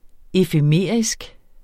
Udtale [ efəˈmeˀɐ̯isg ]